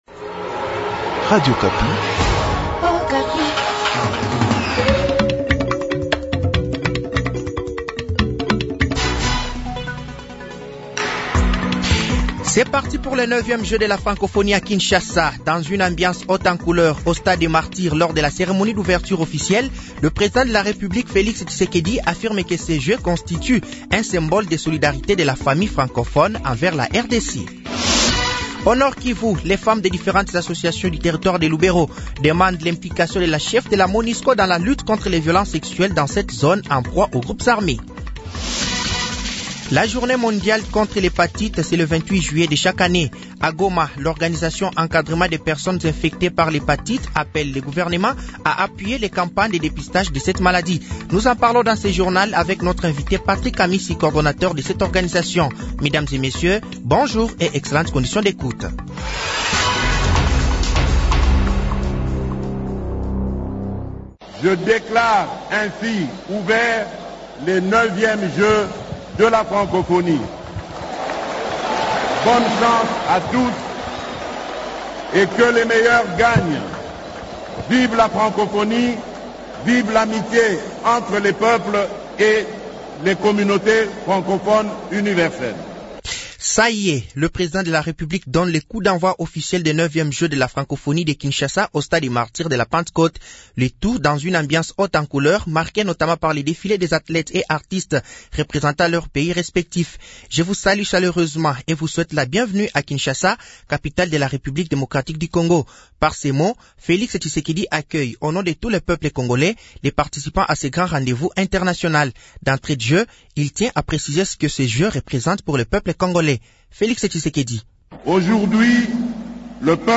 Journal français de 7h de ce samedi 29 juillet 2023